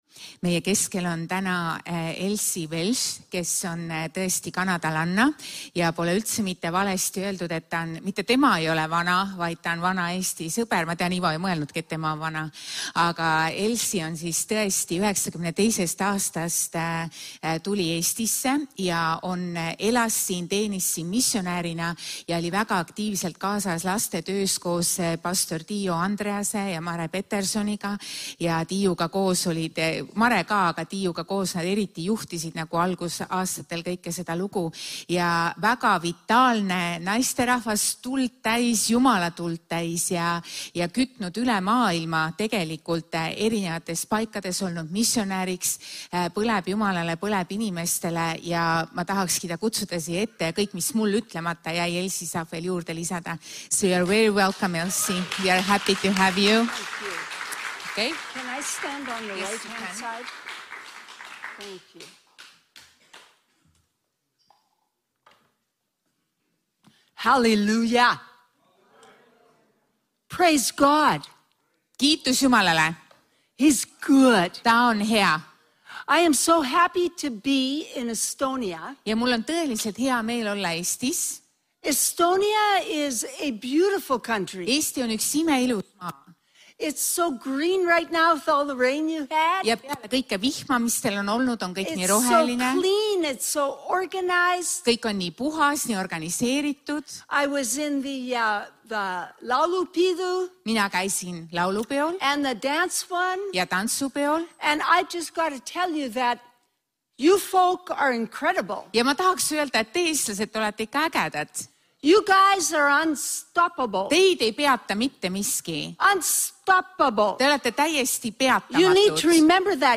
Jutlused - EKNK Toompea kogudus
Jutlus